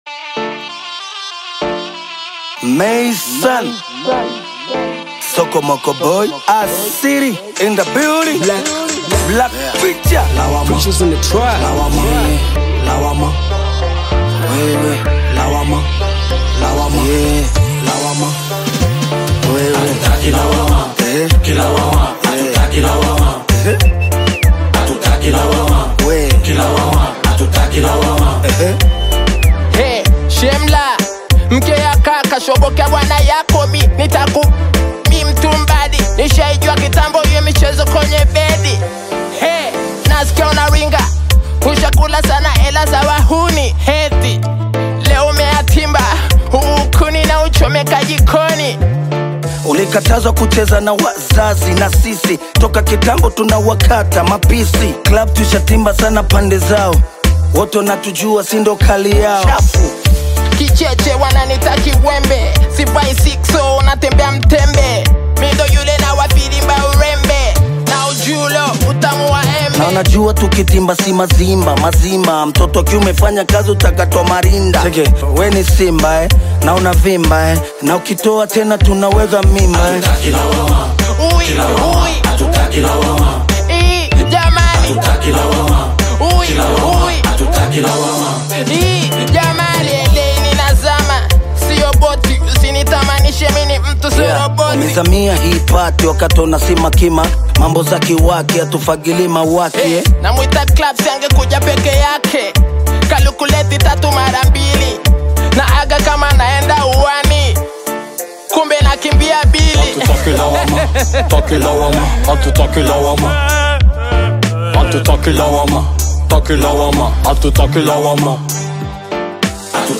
Immerse yourself in the captivating rhythms of